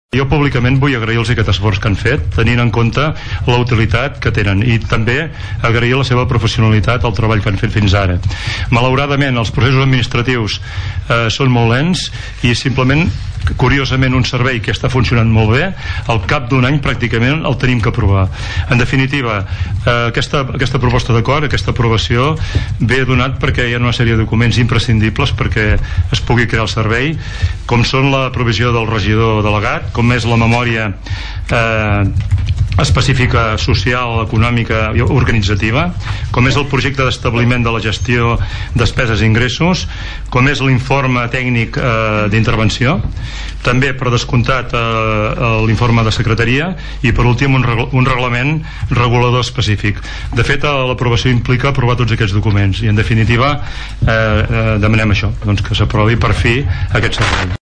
Àngel Pous, regidor de Serveis Socials, va agrair la tasca que desenvolupen les entitats que el gestionen i va explicar en què consisteix l’aprovació del servei, que ha patit un retard administratiu.